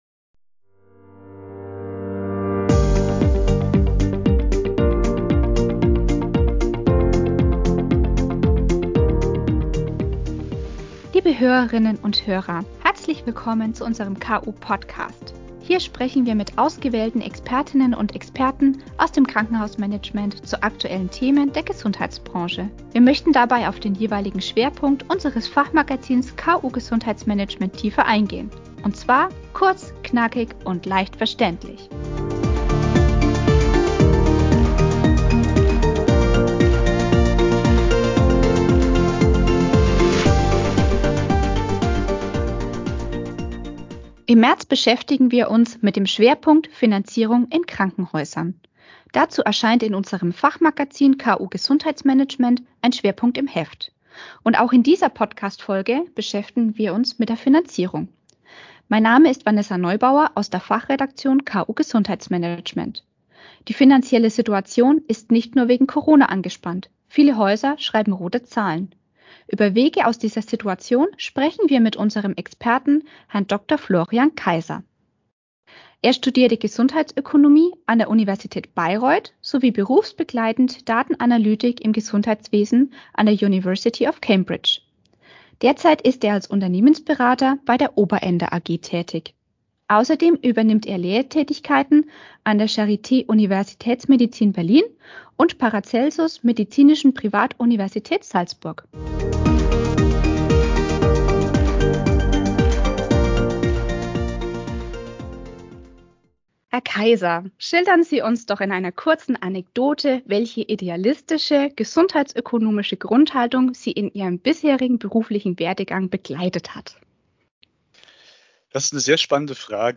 der Interviewpartner unserer Fachredeaktion.